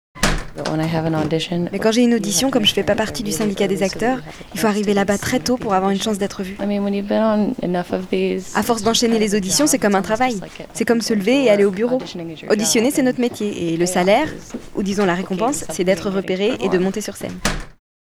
extrait voice over